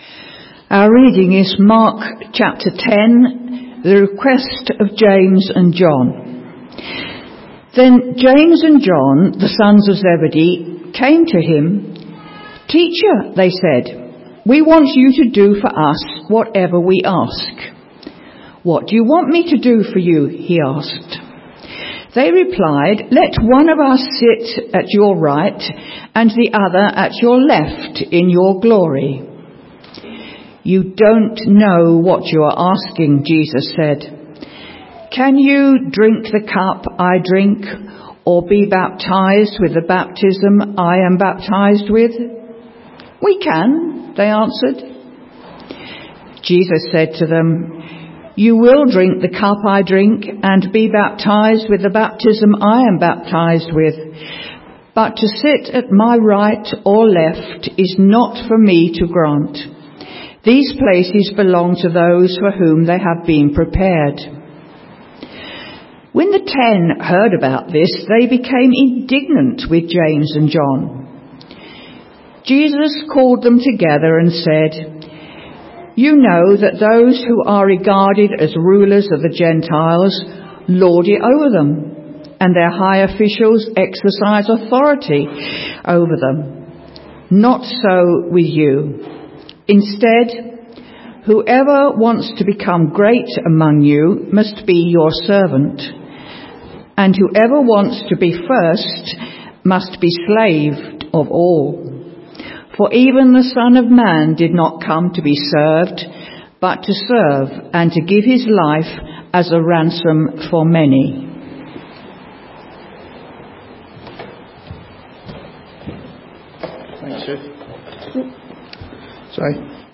A message from the series "Growth Habits."